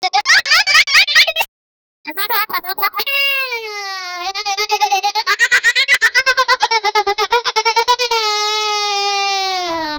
pixel-kid-laugh-p66qm6h2.wav